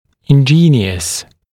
[ɪn’ʤiːnɪəs][ин’джи:ниэс]изобретательный, искусный, оригинальный